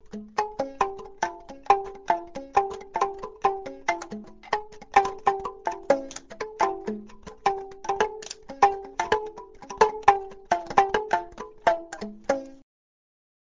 cithare yomkwo, enregistrement personnel